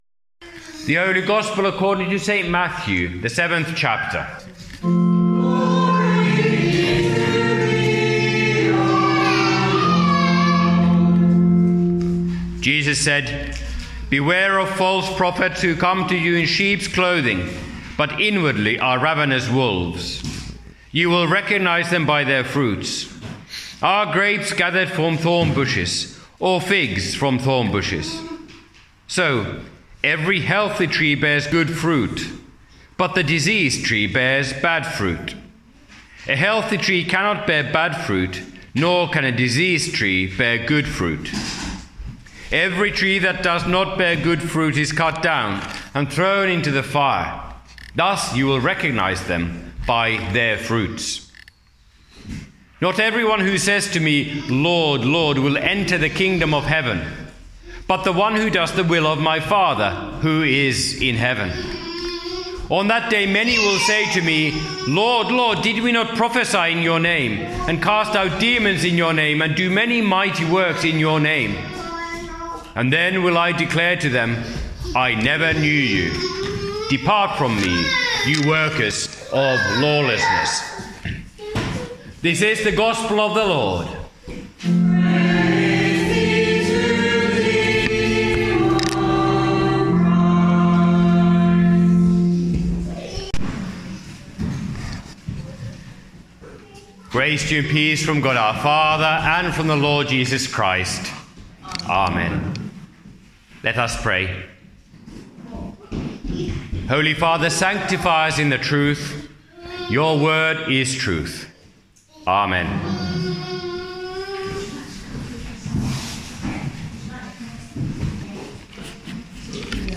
by admin | Aug 10, 2025 | Sermons, Trinity, Trinity 8